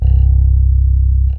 Bass Rnb 2.wav